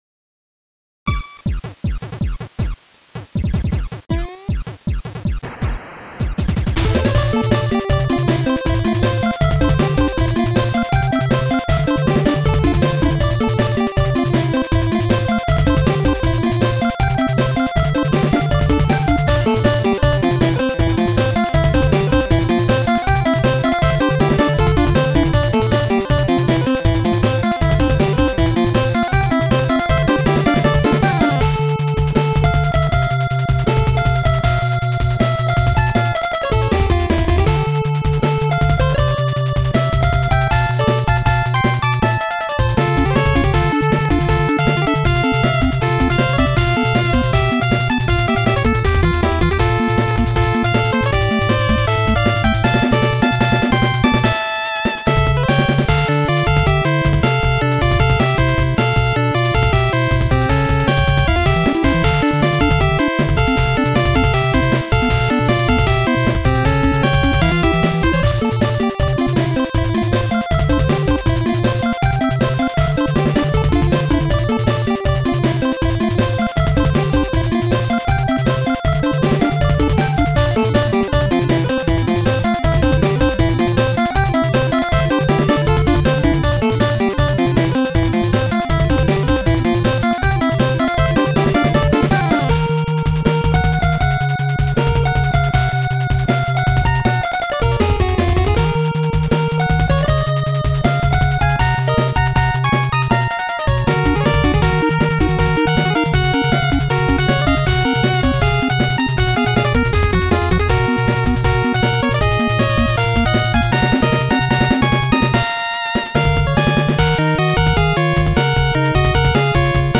東方楽曲をファミコン音源に移植しました的な
Music Arrange